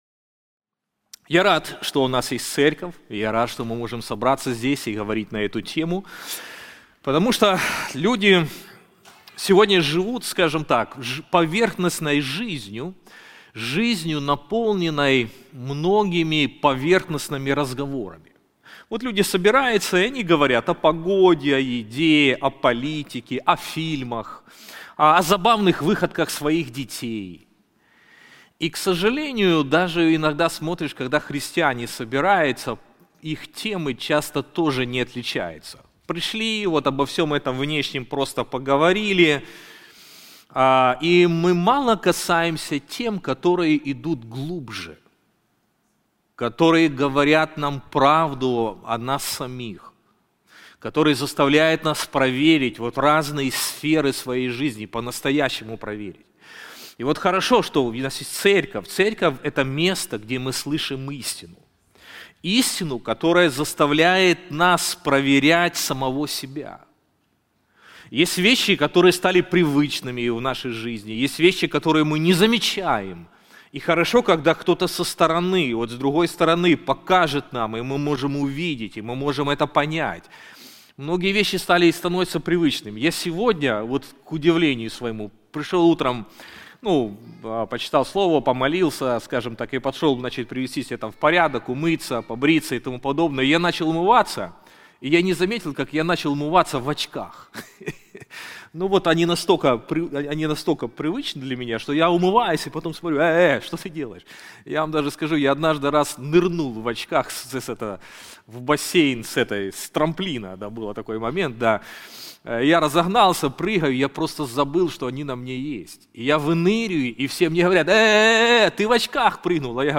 Мы подготовили специальный семинар о библейских принципах управления финансами.